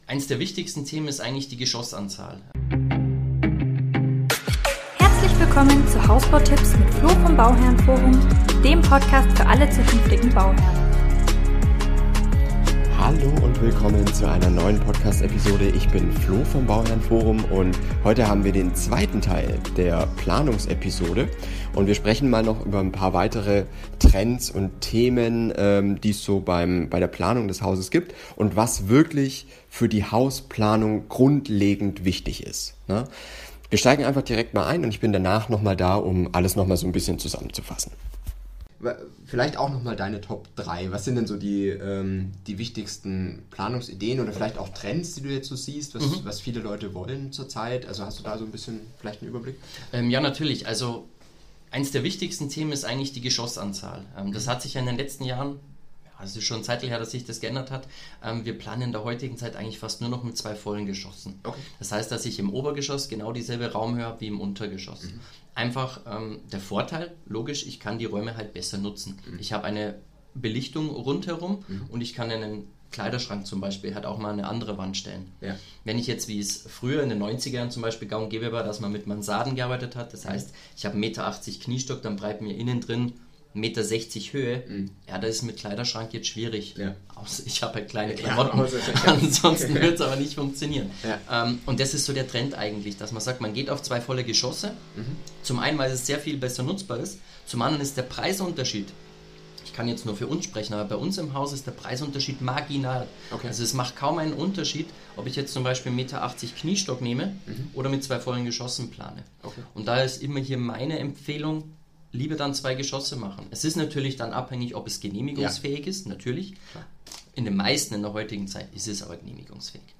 Für die heutige Interview-Episode habe ich mich mit einem Hausplaner zusammengesetzt und über Tipps zur perfekten Planung gesprochen.